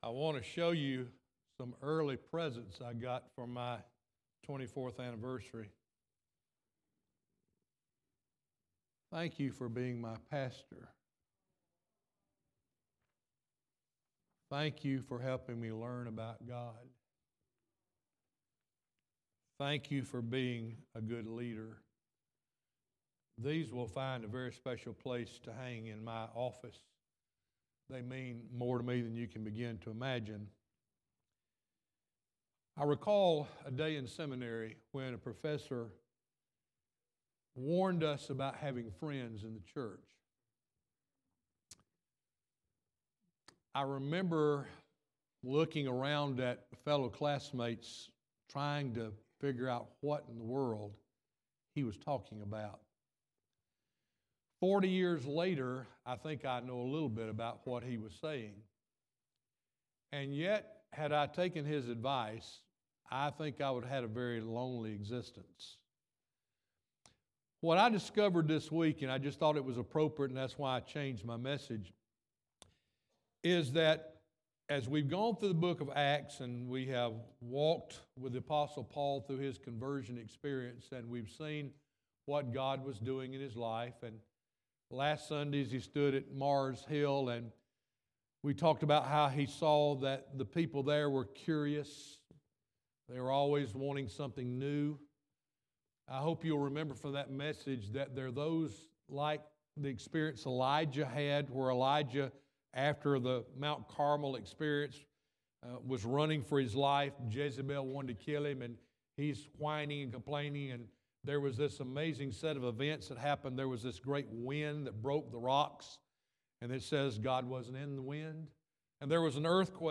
Piney Grove Baptist Church Sermons